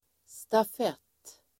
Uttal: [staf'et:]